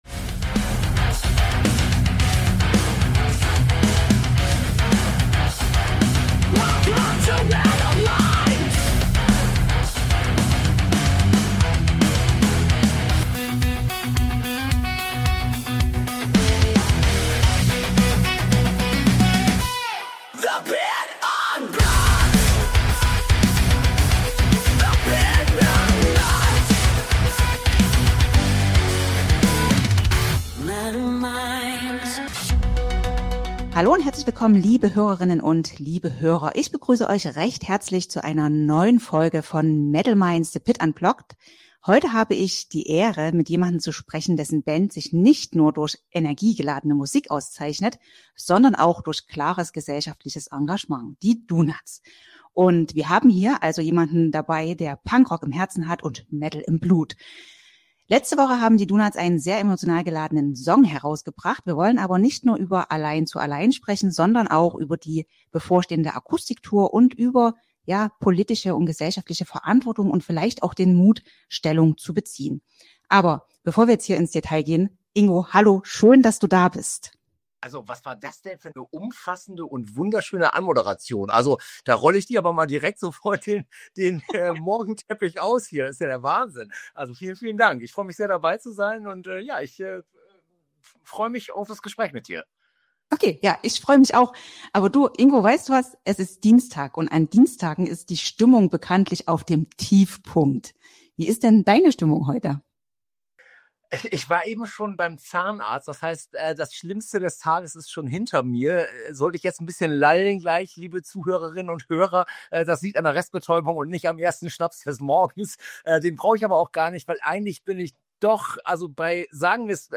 In der neuen Folge von Metal Minds: The Pit Unplugged sprechen wir mit Ingo von den DONOTS über den neuen Song „Allein zu allein“, die kommende Akustik-Tour und warum Musik Haltung zeigen muss. Ein Gespräch voller Emotionen, Ehrlichkeit und Punkrock-Spirit.